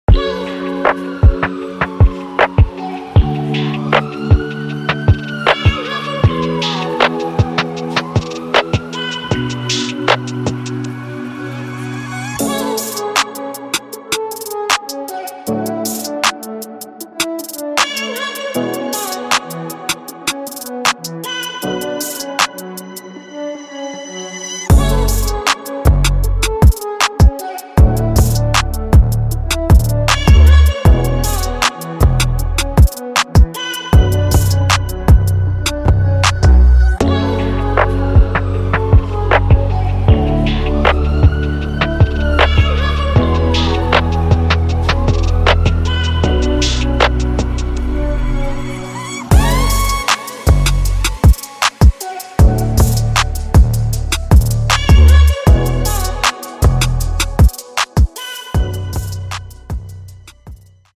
Neo Soul